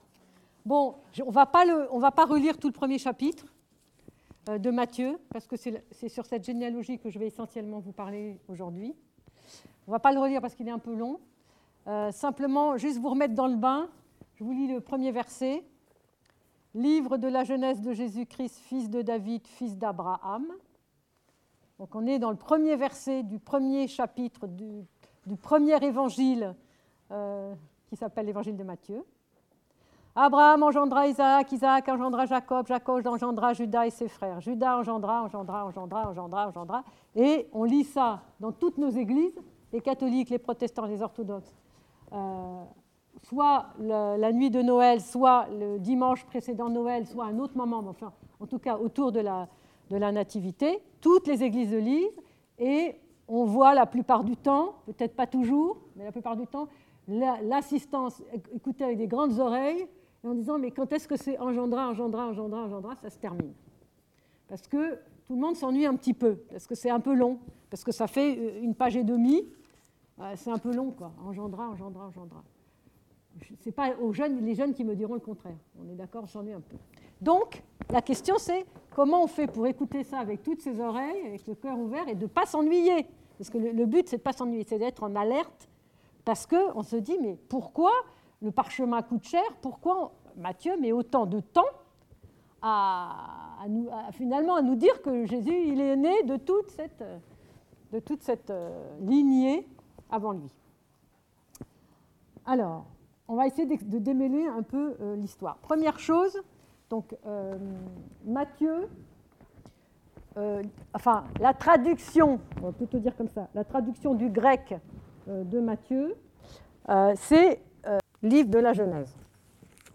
Conférence disponible aussi en vidéo